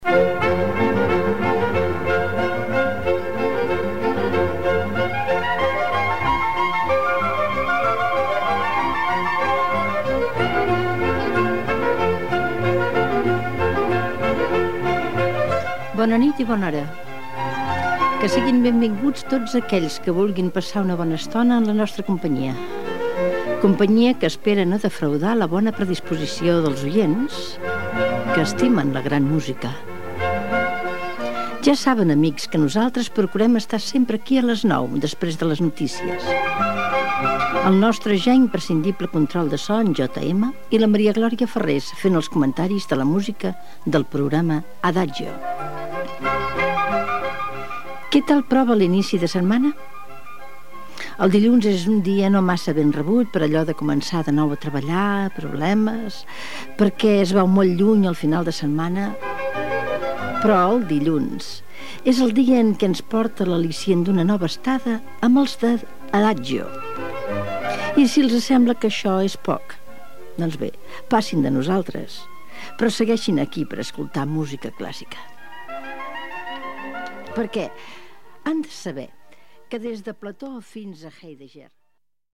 Sinronia i presentació del programa Gènere radiofònic Musical